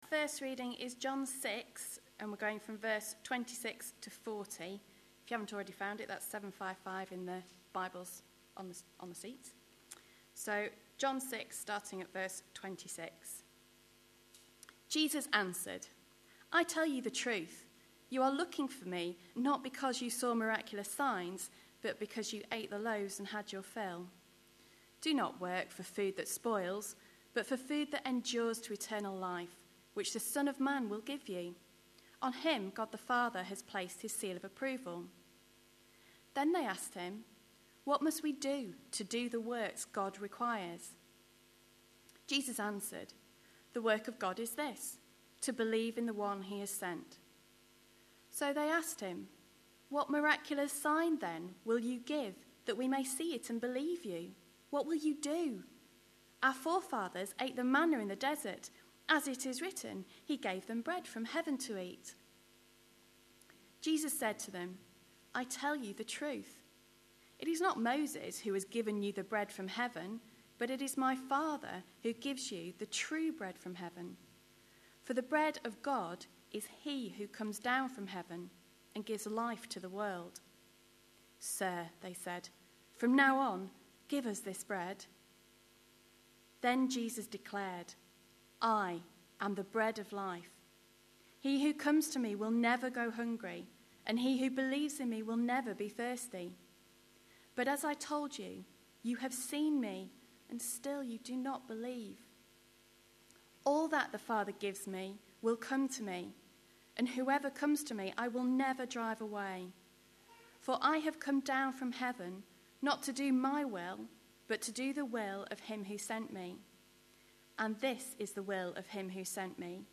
A sermon preached on 4th September, 2011, as part of our Distinctives series.
John 6:26-40 Listen online Details The last fifteen minutes are at a lower recording quality than the rest of the talk.